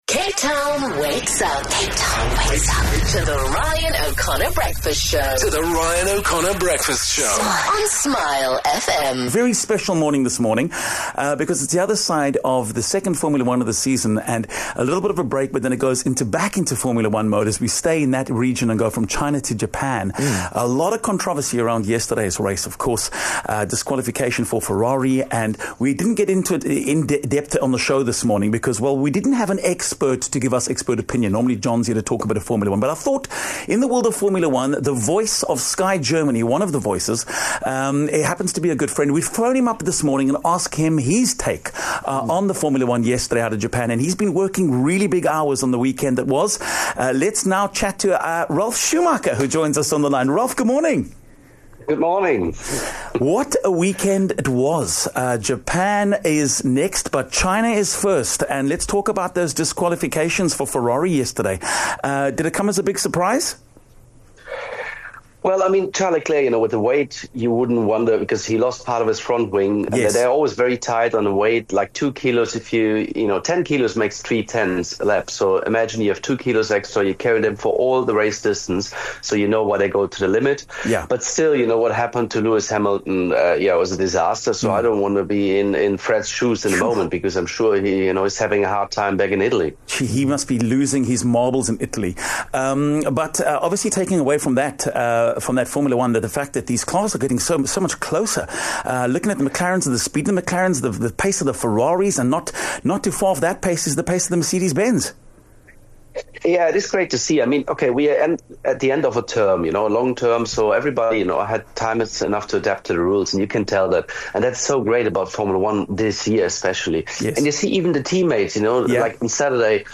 24 Mar Ralf Schumacher joined us for a chat
There was lots to talk about around this past weekend's Chinese Grand Prix. Broadcaster and former Formula One racing driver Ralf Schumacher joined us on the show this morning to help unpack it all.